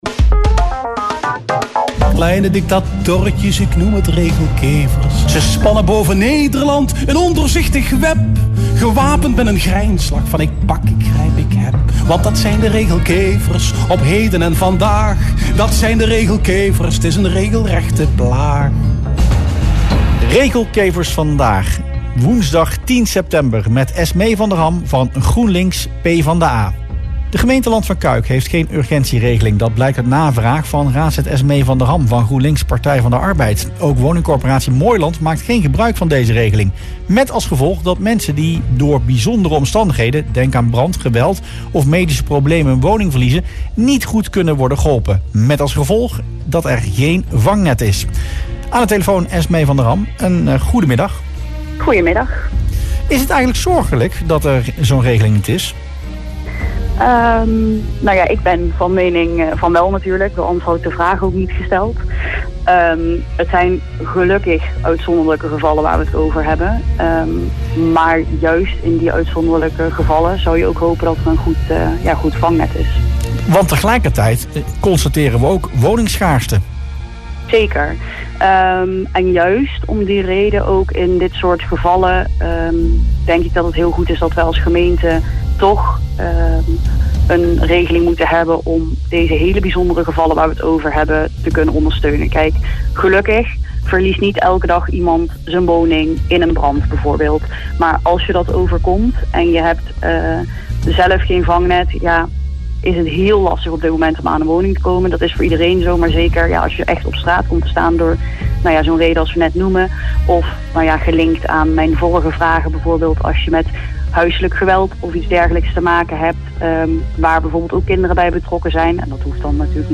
Dat zei ze in radioprogramma Rustplaats Lokkant.
Esmee van der Ham (GroenLinks/PvdA) in Rustplaats Lokkant